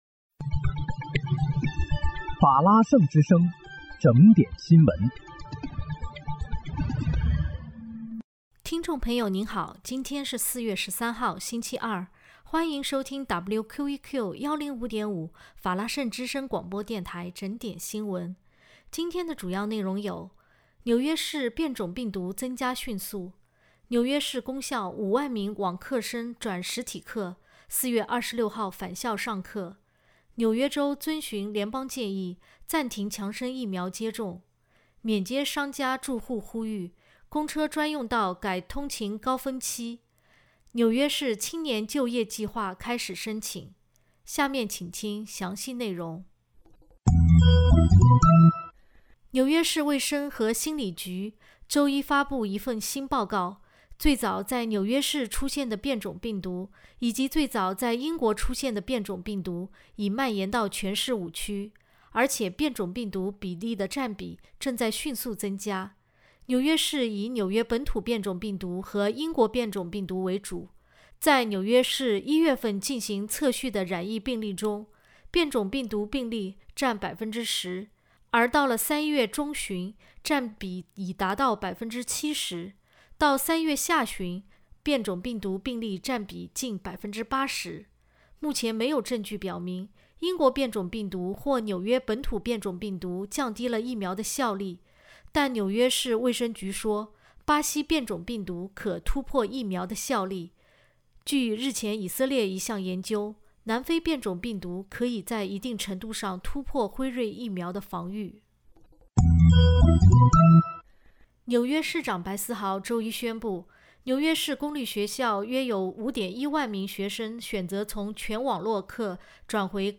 4月13日（星期二）纽约整点新闻
听众朋友您好！今天是4月13号，星期二，欢迎收听WQEQ105.5法拉盛之声广播电台整点新闻。